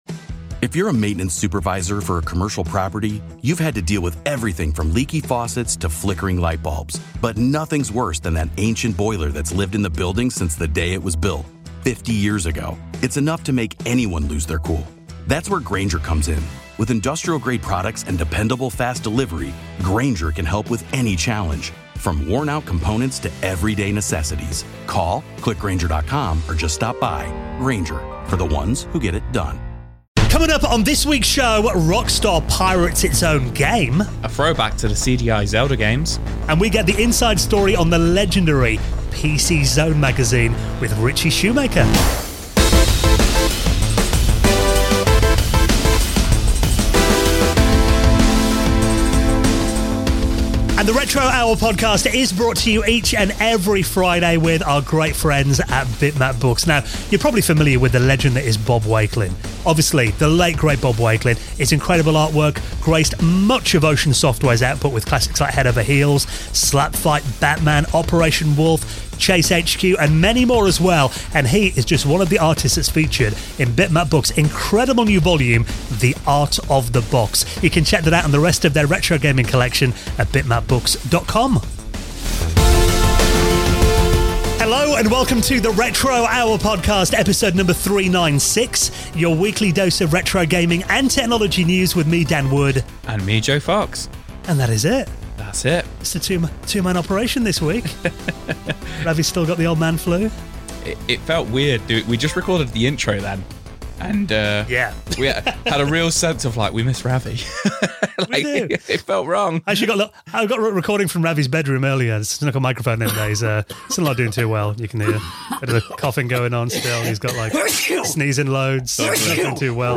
PC Zone Interview